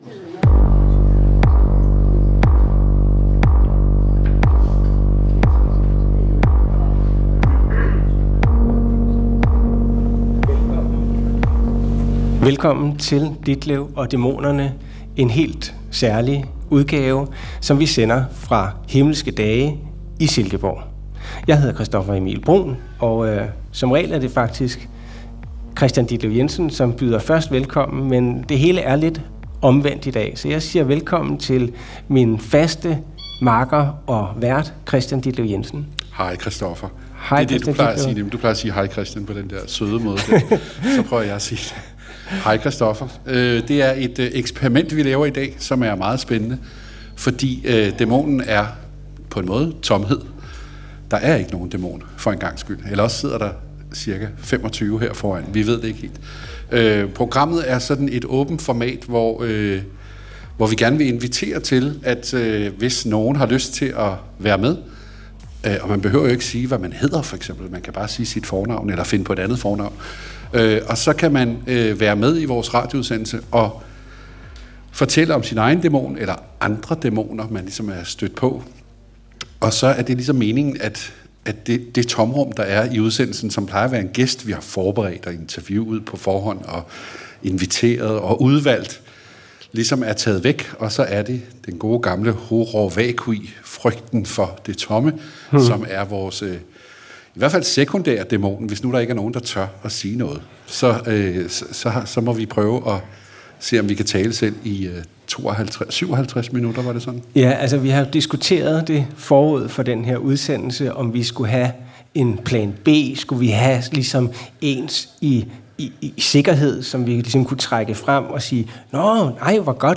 På Hinmelske Dage - kirkernes folkemøde i Silkeborg - er publikum inviteret til at fortælle om deres egne dæmoner. Vært: Kristian Ditlev Jensen.